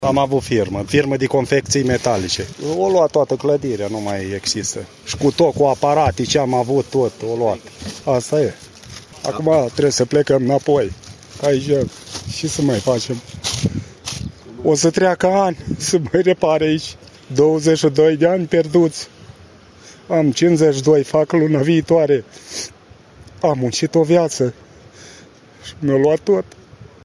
Corespondență de la fața locului